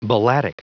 Prononciation du mot balladic en anglais (fichier audio)
Prononciation du mot : balladic